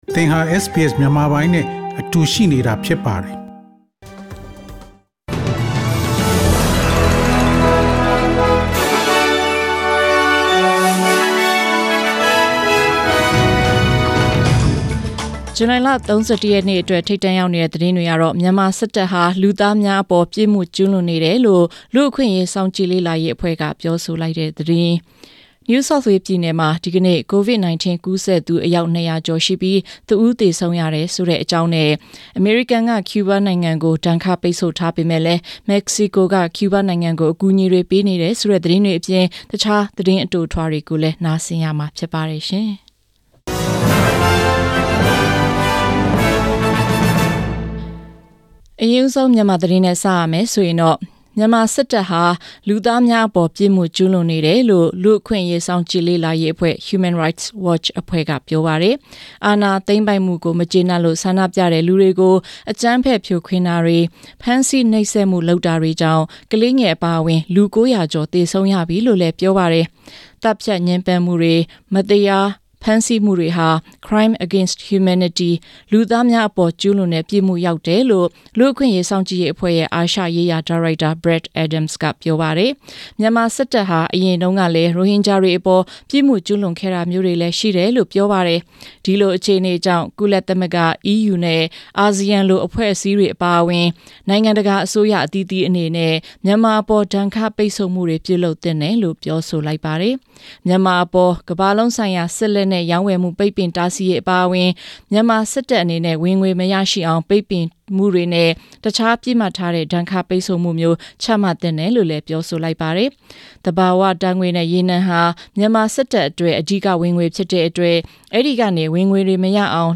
SBS မြန်မာပိုင်း အစီအစဉ် ပေါ့ကတ်စ် သတင်းများ။ Source: SBS Burmese